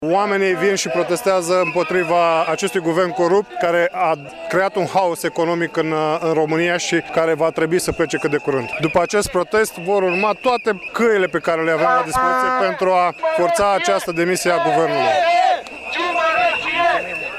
Liderilor consilierilor judeţeni ai PNL Iaşi, Romeo Vatră: